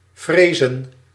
Ääntäminen
IPA: [kʁɛ̃dʁ]